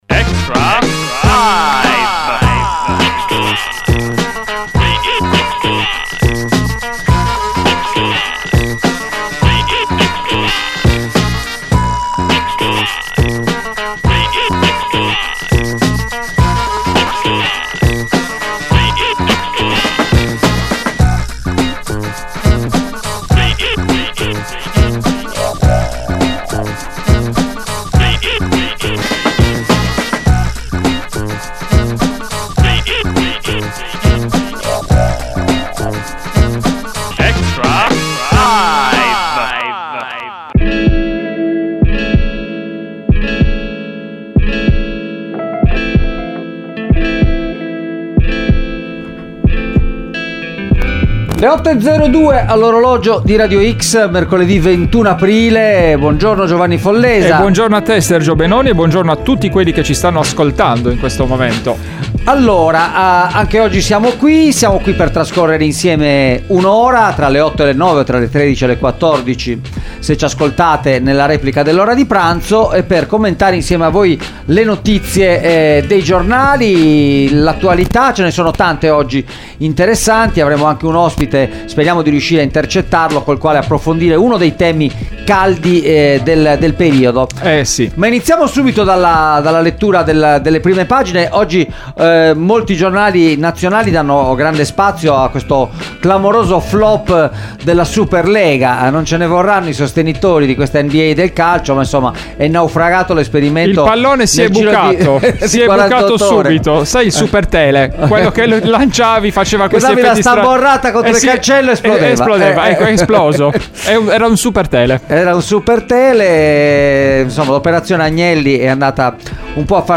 Extralive mattina: ogni giorno in diretta dalle 8 alle 9 e in replica dalle 13, il commento alle notizie di giornata dalle prime pagine dei quotidiani con approfondimenti e ospiti in studio.